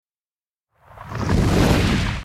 Big_Explosion_Sweeping_In.mp3